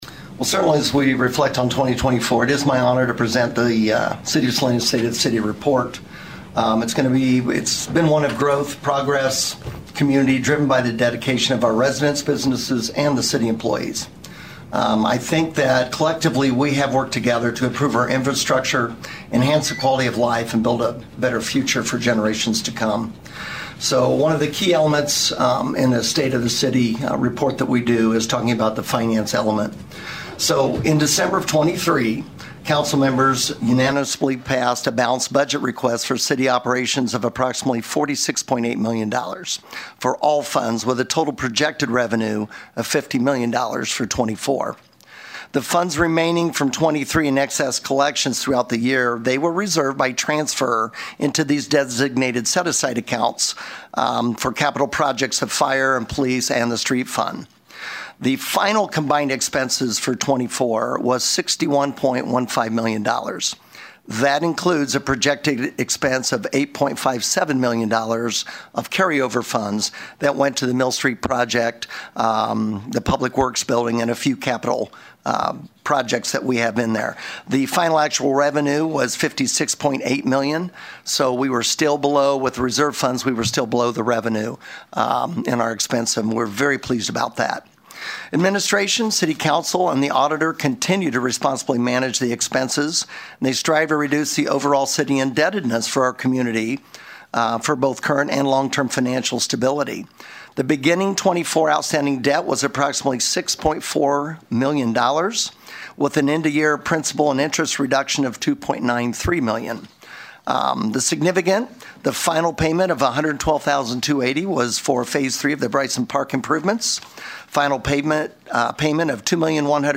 To hear Mayor Jeff Hazel give the State of the City Address: